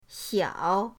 xiao3.mp3